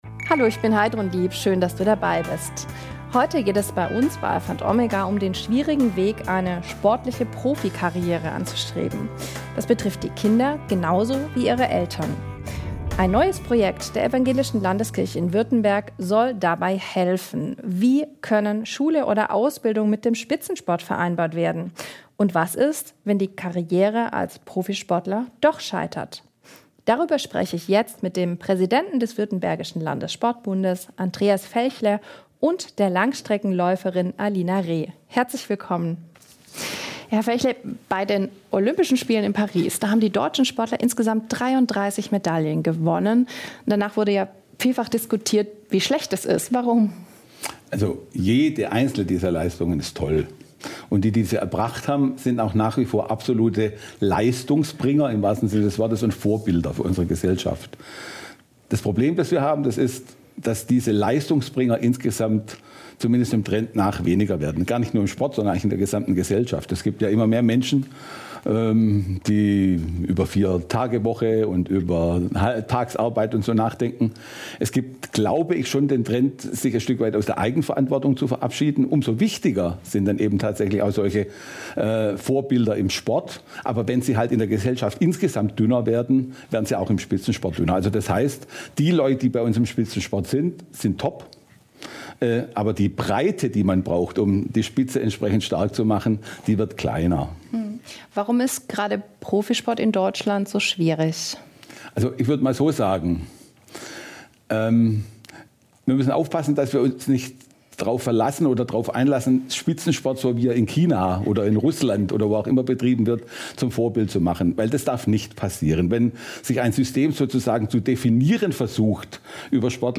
In dieser Folge sprechen wir mit Langstreckenläuferin Alina Reh